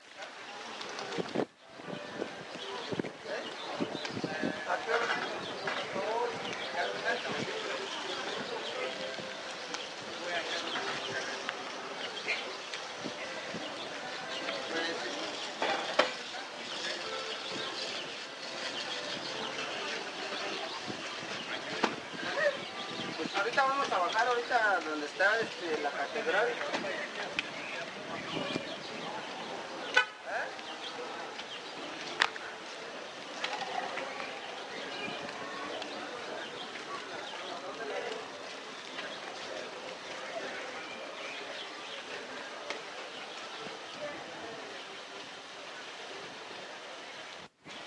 Caminata sonora Dos
Lugar: Oaxaca, Oaxaca; Mexico.
Equipo: Sony Cassette-Corder TCM-200DV